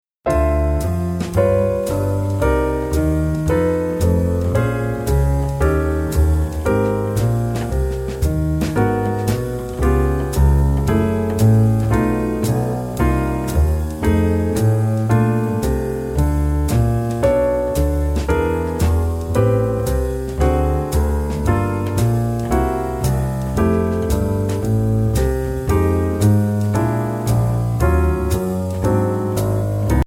Voicing: Jazz Keyboard